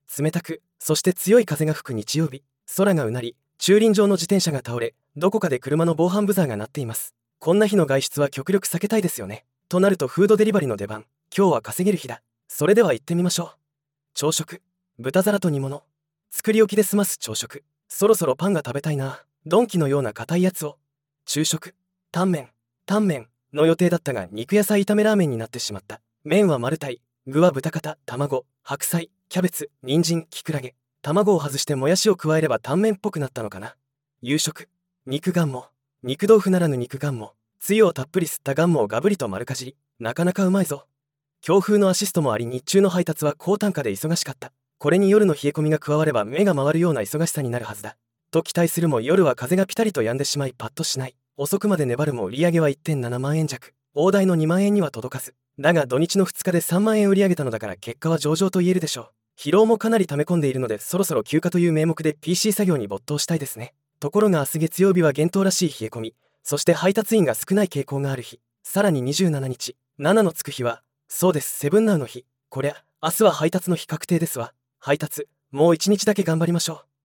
冷たく、そして強い風が吹く日曜日。
空が唸り、駐輪場の自転車が倒れ、どこかで車の防犯ブザーが鳴っています。